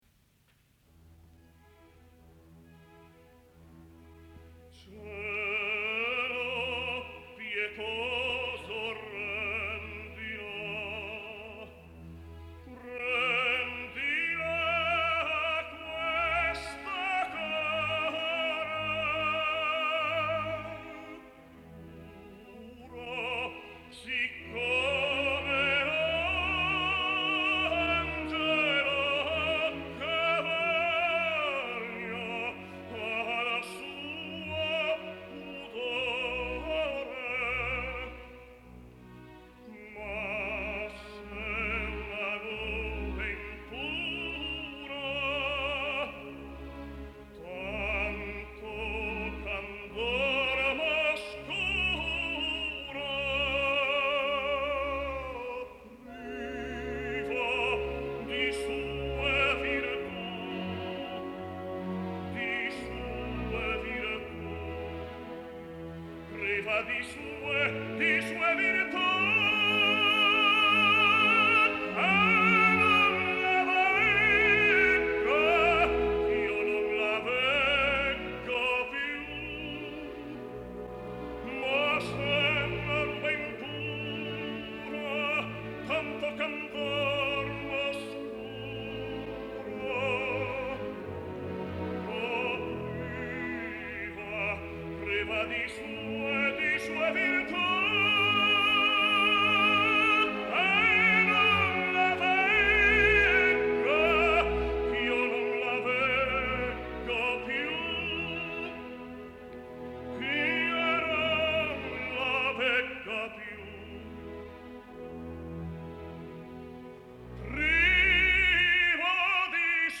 Несколько записей итальянского тенора Даниеле Бариони (1930 г.р).